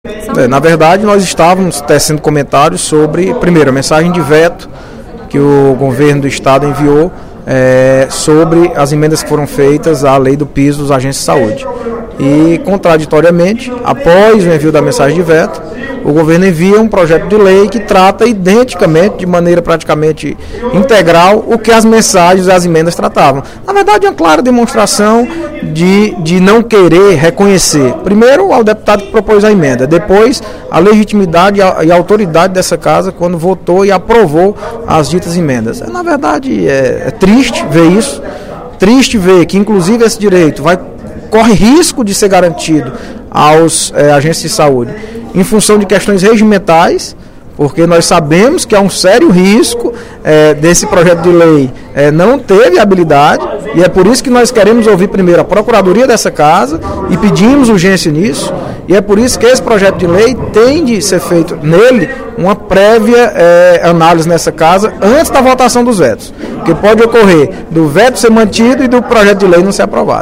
O deputado Audic Mota (PMDB) informou, durante o primeiro expediente da sessão plenária desta terça-feira (31/03), que protocolou um pedido para que a mensagem nº 7.729, do Poder Executivo, que trata do reajuste salarial dos agentes comunitários de saúde, seja votada em regime de urgência.